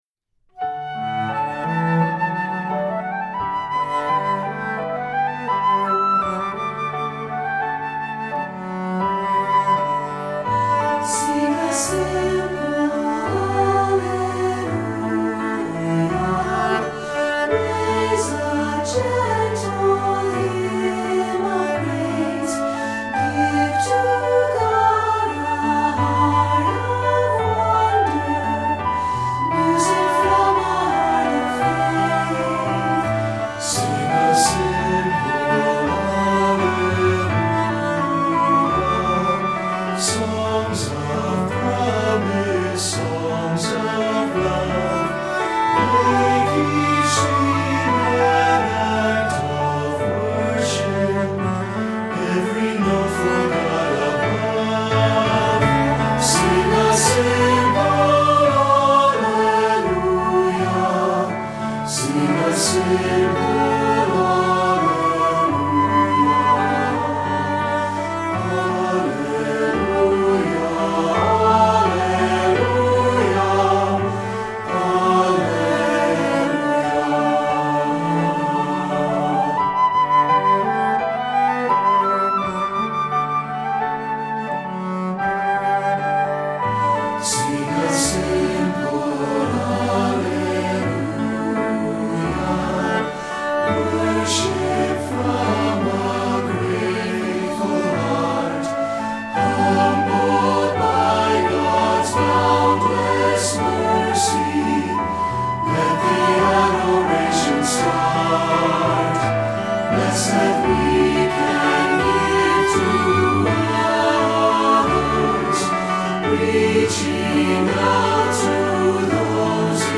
Voicing: SATB, Flute and Cello